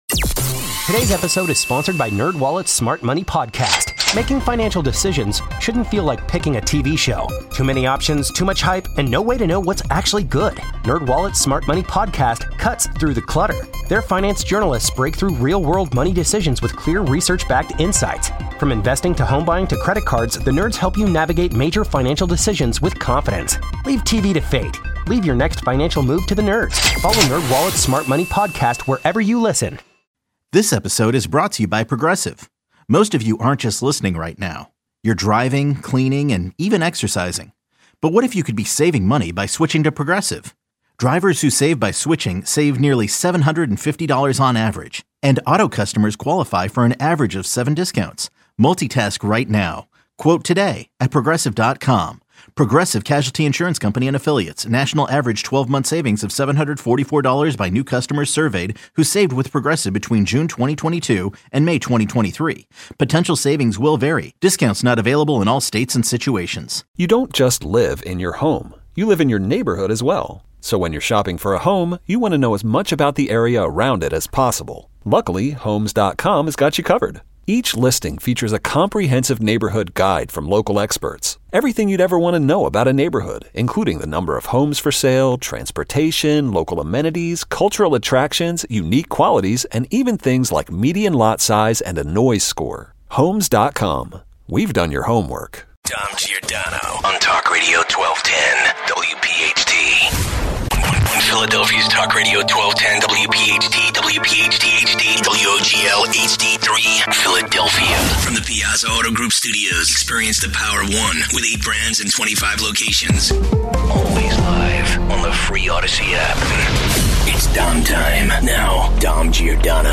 220 - We continue to play audio of Congresspeople attacking the sanctuary city mayors and those attacking Trump.
Your calls. 250 - The Lightning Round!